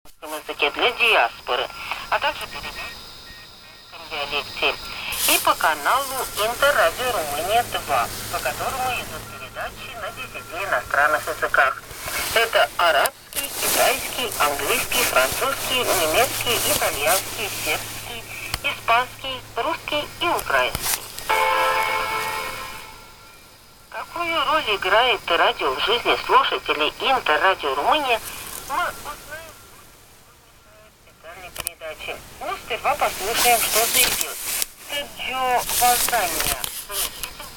"Прогуляться" наиболее мотивированно, для коротковолновика, с QRP/p. Взял ТПП "Приятель-8" на 10116 кГц.
Сегодня капитально мешало "Радио Румынии". Это издержки приёма прямого преобразования.
Кому интересно, сообщение прилагаю и пару аудио-записей, что бы и минусы простых аппаратов прямого преобразования были видны.